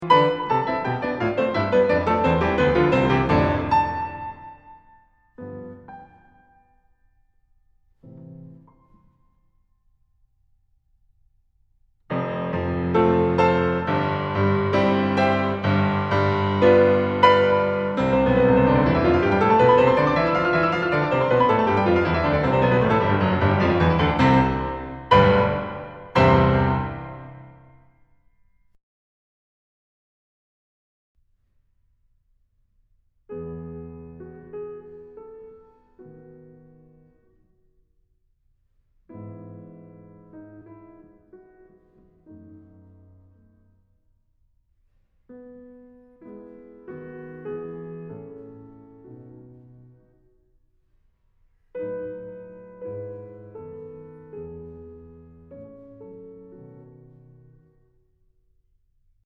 Listen to this, first the end of the first movement, and then the start of the slow movement in that magic E Major.
(Unlike in the post on the first movement, this is not basement recordings anymore, this is from the future CD, yipii)